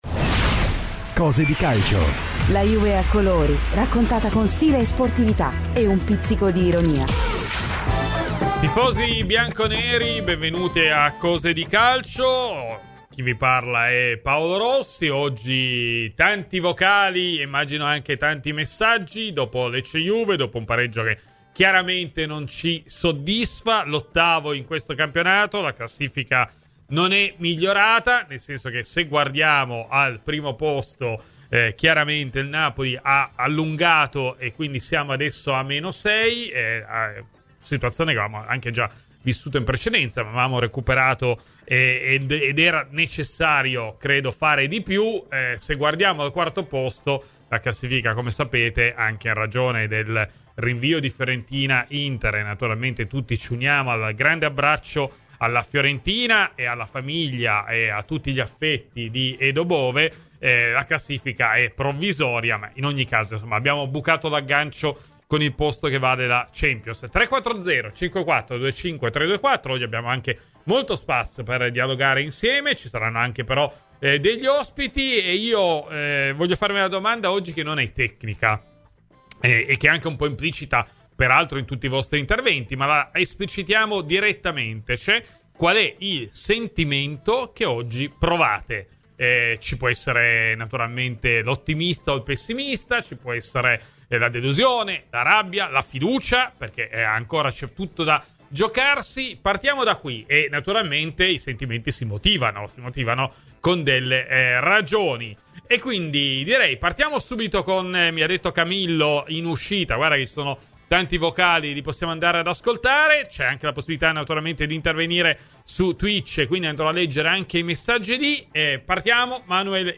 Intervenuto nel corso di "Cose di Calcio" su Radio Bianconera